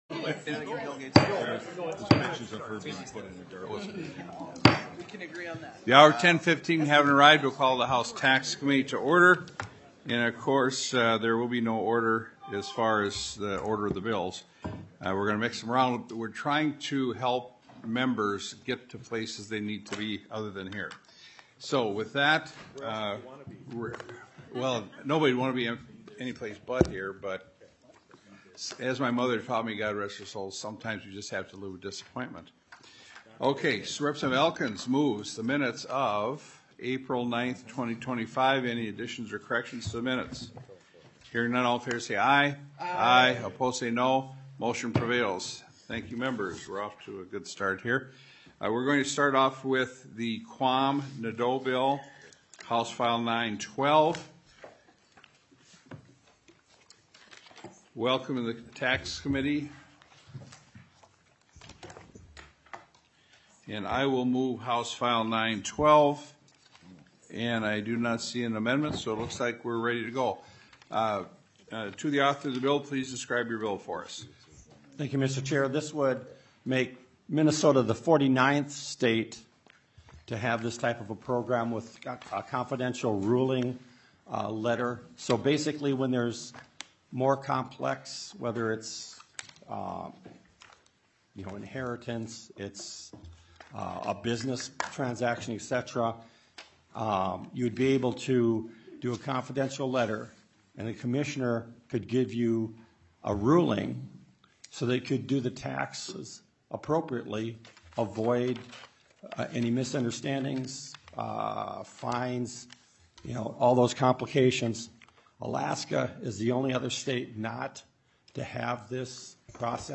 Taxes TWENTY-SIXTH MEETING - Minnesota House of Representatives